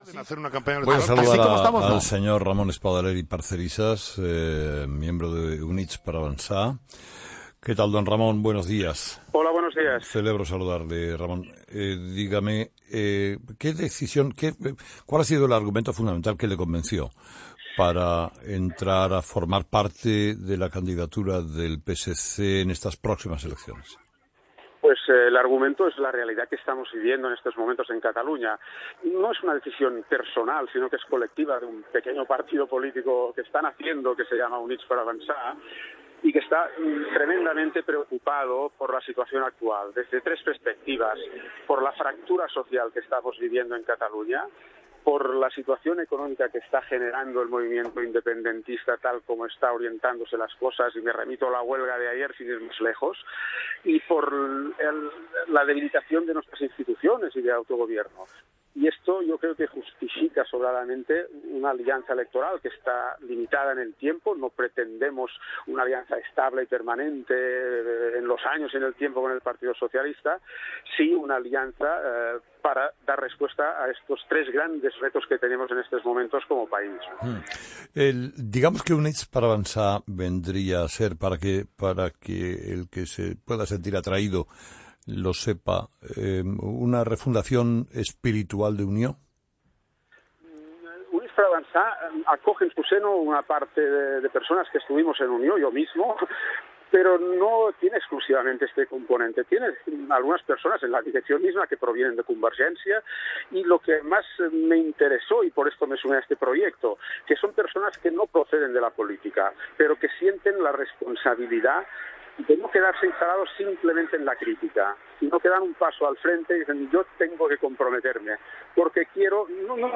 Entrevista a Ramón Espadaler COPE